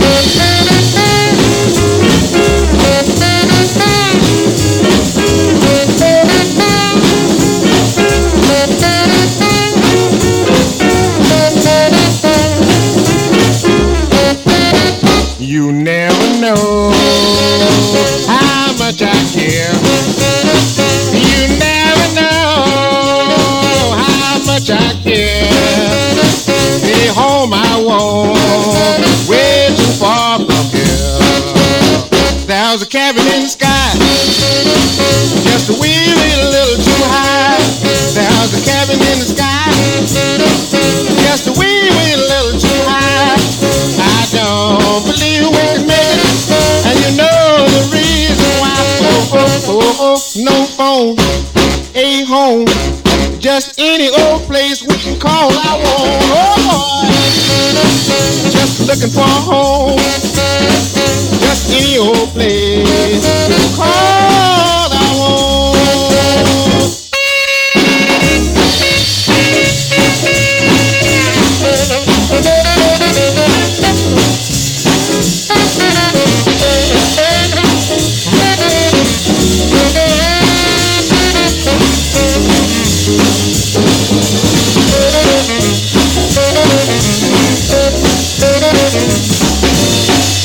SOUL / RHYTHM & BLUES / BLUES / ROCK & ROLL / OLDIES